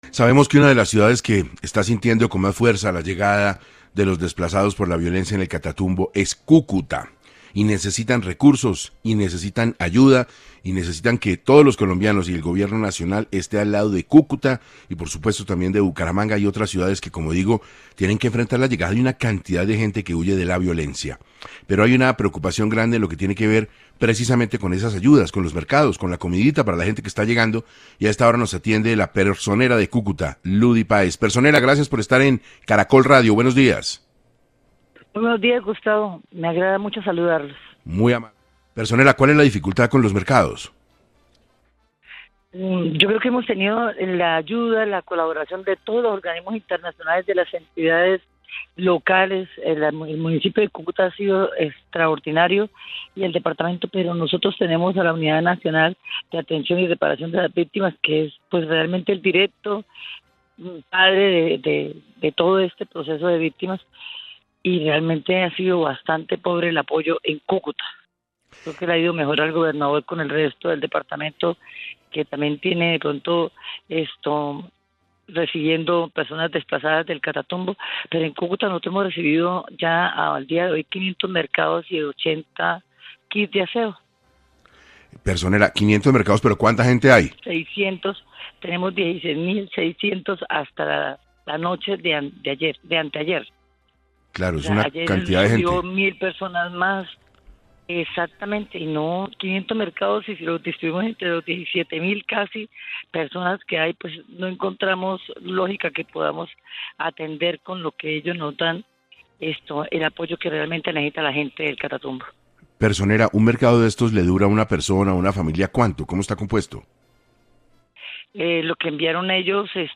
Luddy Páez aseguró en 6AM que la preocupación es muy grande ante la crisis humanitaria y la falta de atención adecuada por parte del Gobierno Nacional.